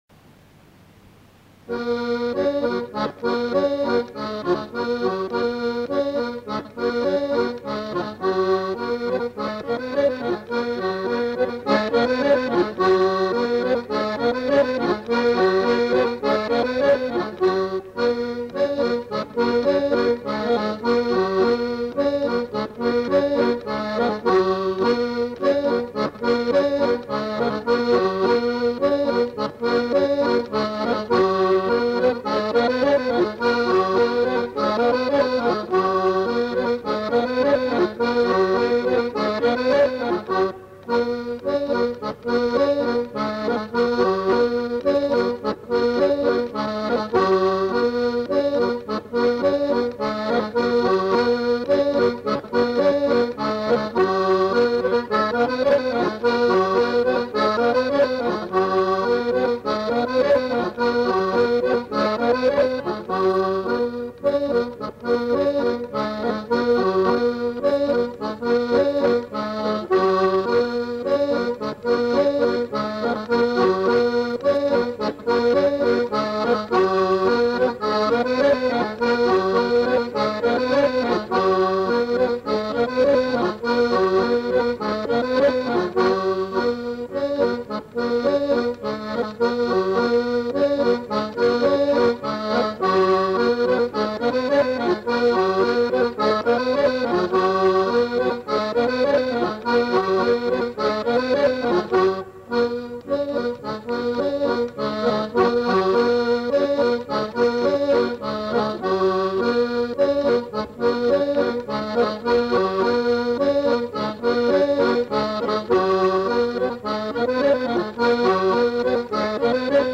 Instrumental. Accordéon diatonique
Lieu : Monclar d'Agenais
Genre : morceau instrumental
Instrument de musique : accordéon diatonique